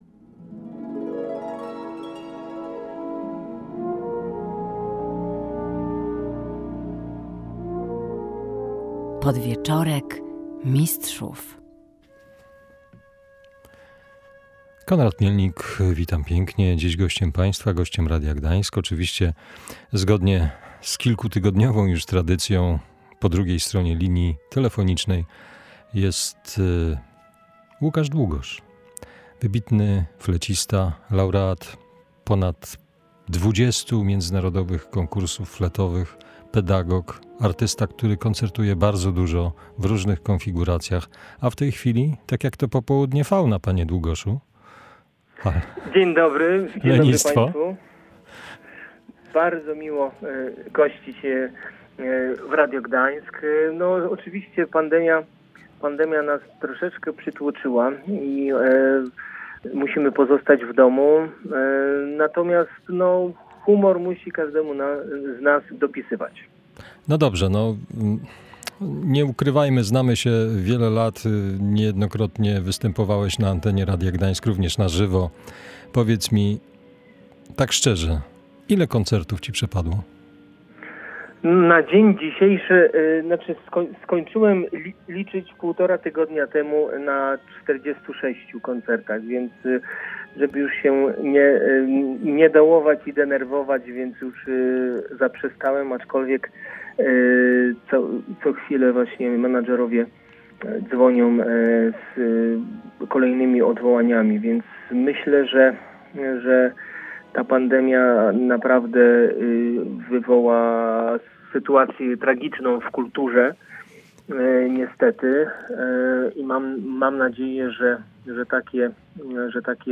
O tym i o rządowym wsparciu dla polskiej kultury, rozmawialiśmy w Podwieczorku Mistrzów